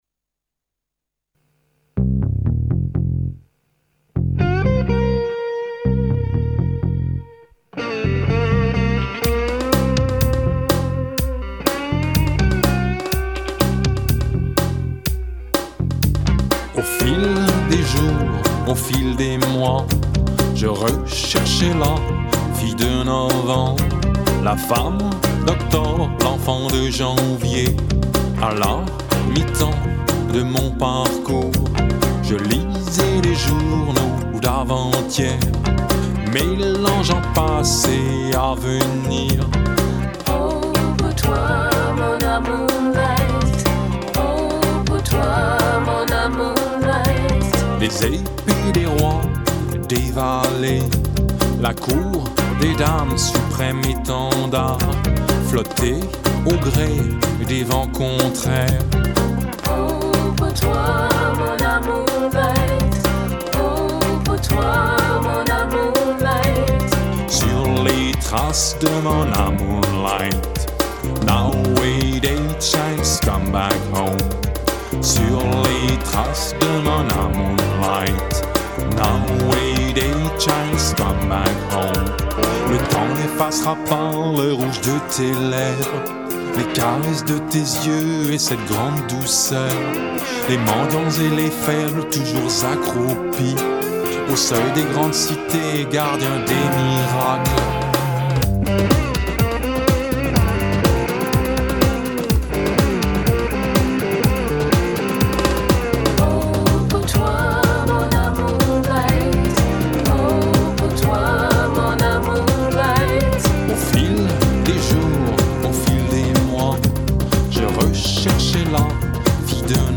rythm guitar + acoustic guitars